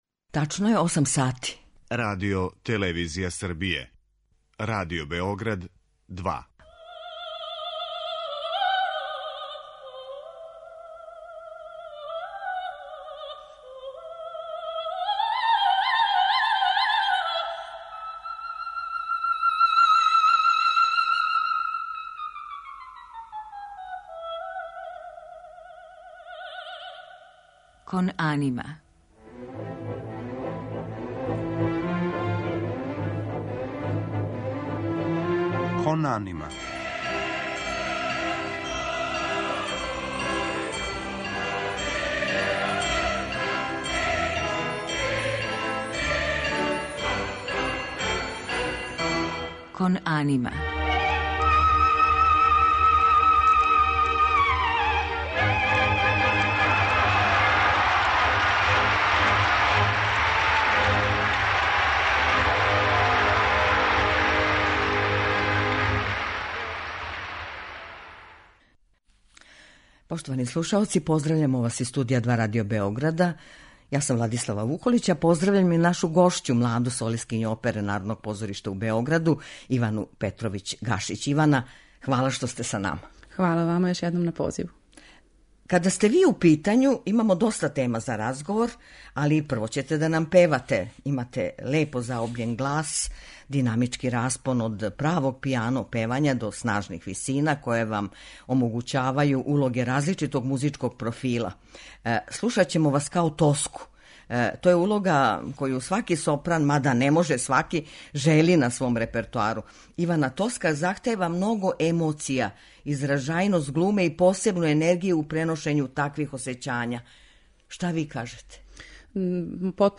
а у музичком делу биће емитовани фрагменти из опера Штрауса, Пучинија и Вердија, у њеном извођењу.